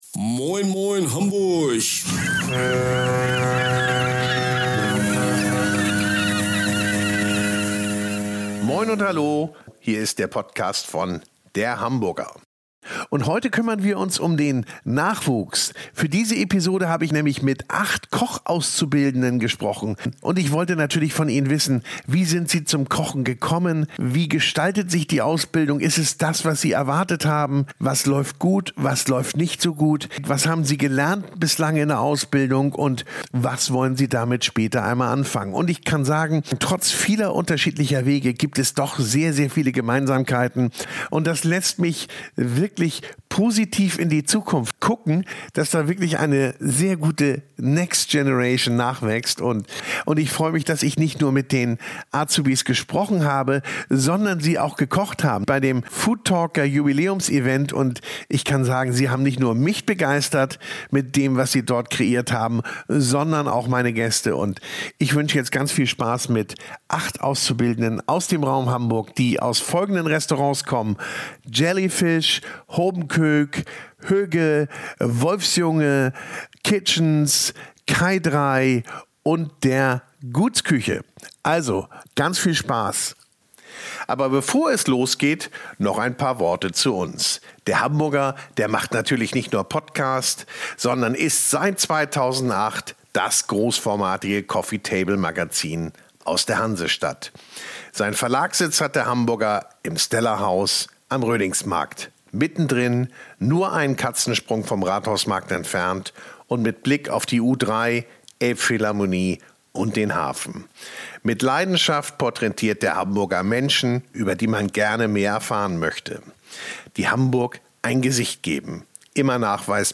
Acht Köche von morgen in einem Talk: Wie ist es in den Küchen renommierter Restaurants, wenn man gerade noch am Anfang seiner Karriere steht? Erfahrungen, Zukunftspläne, Ehrgeiz – ein ganz exklusiver Einblick in die Lehrzeit der nächsten Generation der Gastronomie.